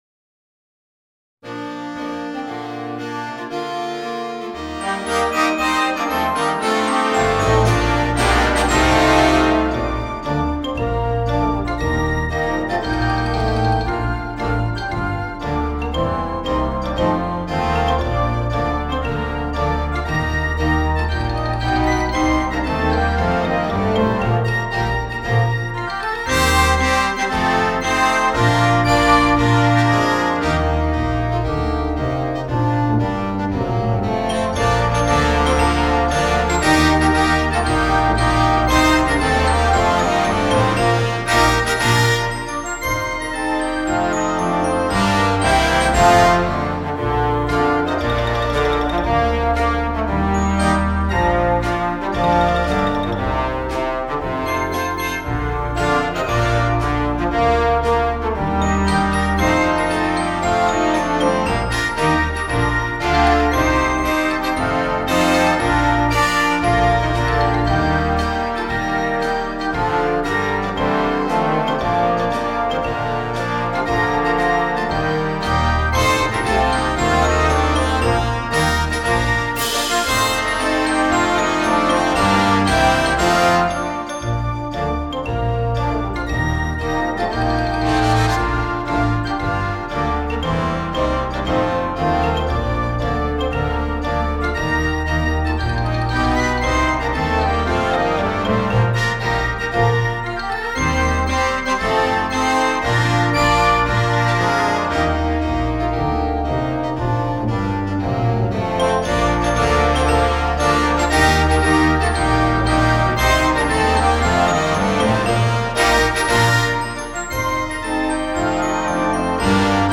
light-hearted arrangement
traditional Australian folksong
for concert band
Folk and World